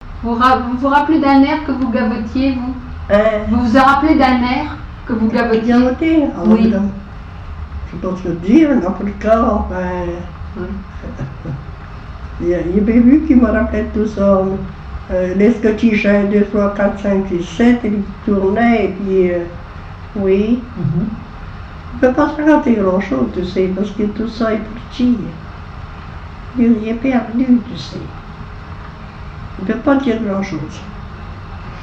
danse (pratique de la) ; chanteur(s), chant, chanson, chansonnette
Catégorie Témoignage